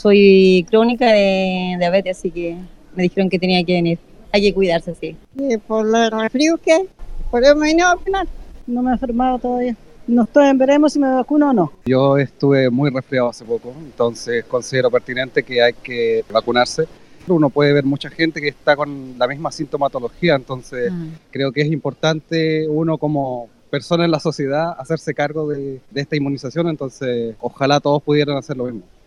Este martes se desarrolló un operativo de vacunación en el centro asistencial para prevenir los contagios. Algunas de las personas asistentes manifestaron su preocupación.
cuna-influenza-gente.mp3